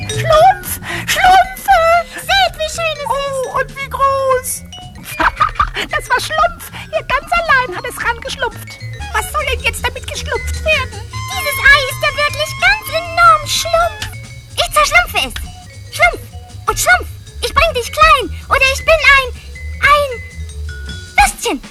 div. Stimmen: